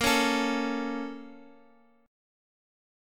A#mM7 chord